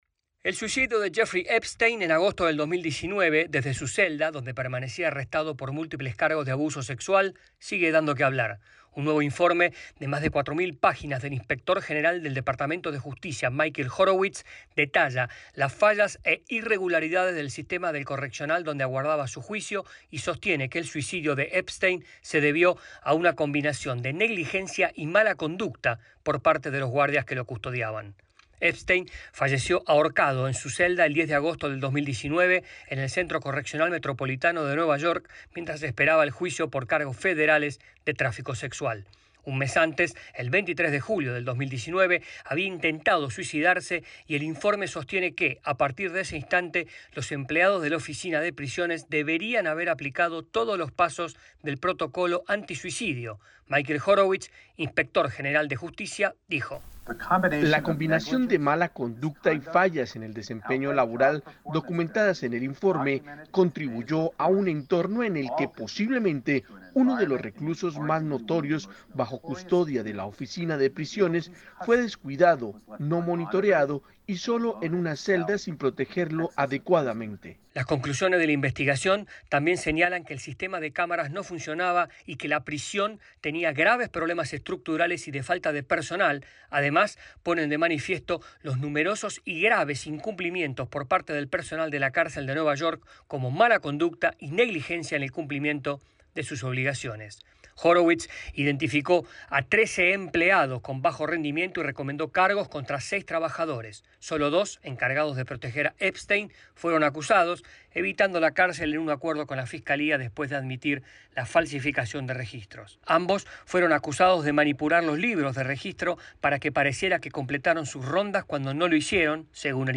AudioNoticias
desde la Voz de América en Washington DC